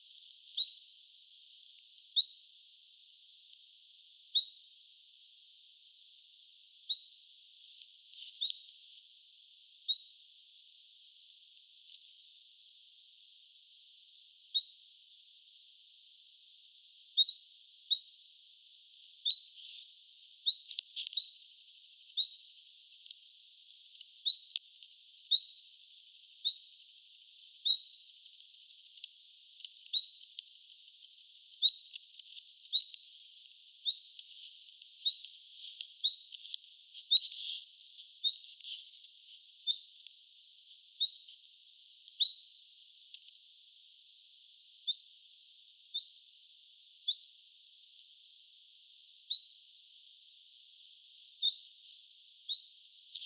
Tässä on sen ääntelyä.
bizt-tiltaltin_aantelya.mp3